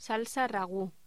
Sonidos: Voz humana